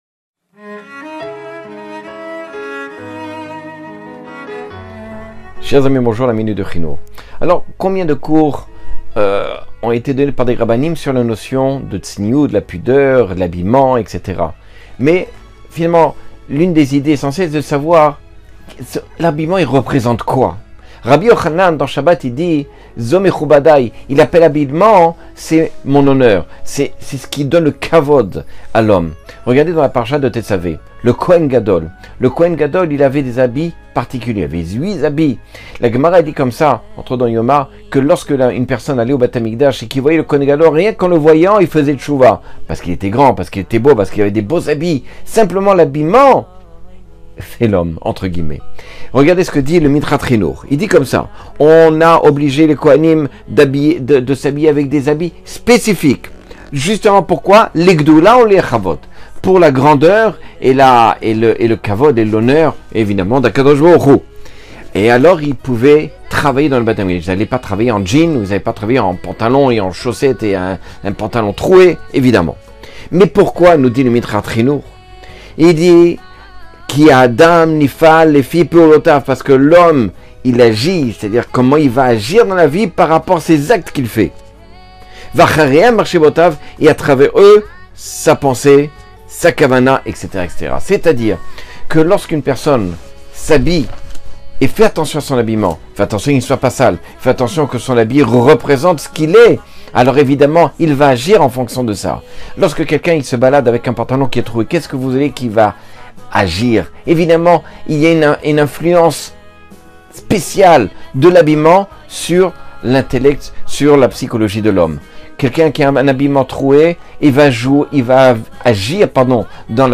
Cours de 1 minute et des poussières sur l’éducation (le ‘hinoukh).